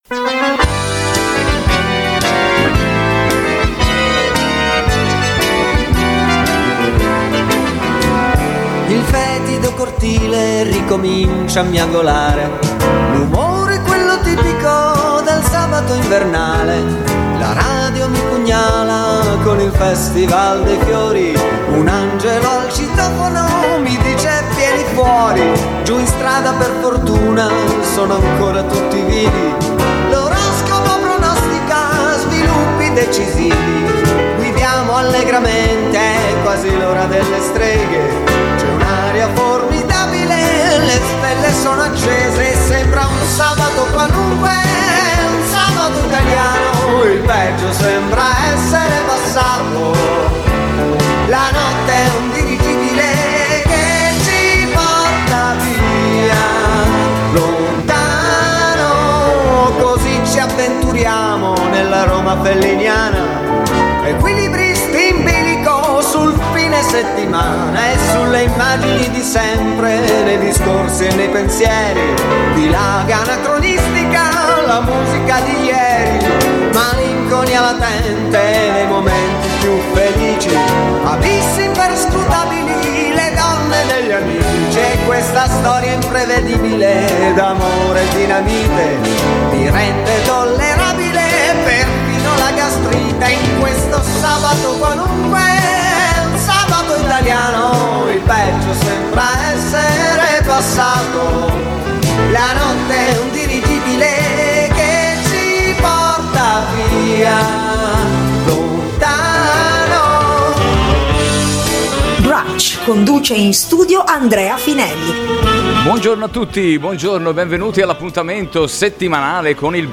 Programma radiofonico "Brunch" - Puntata del 28/01/23 — Codice Ospite
Intervista andata in onda su Radio Giglio Rosso